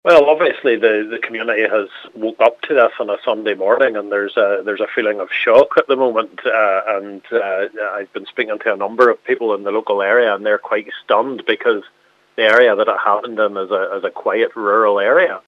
Causeway Coast and Glens Councillor Darryl Wilson says the local community can’t believe what’s happened: